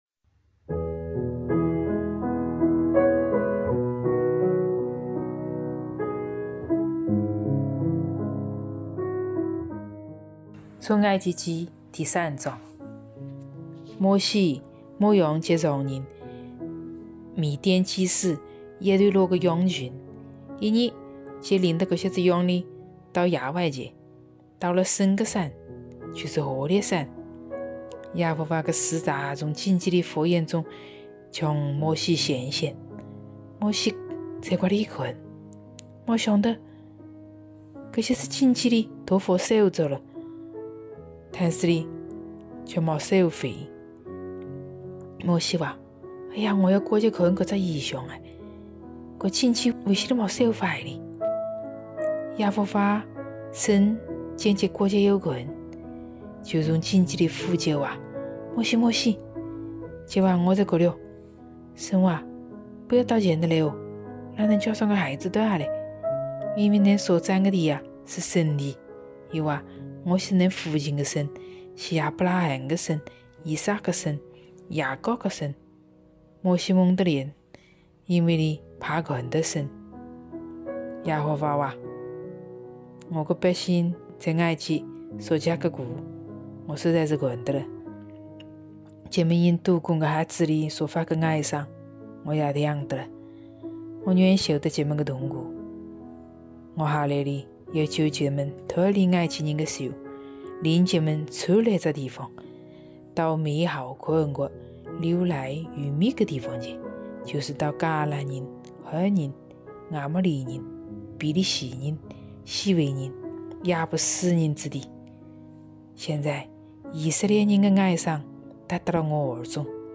语言：南昌话